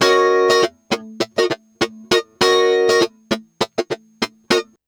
100FUNKY04-R.wav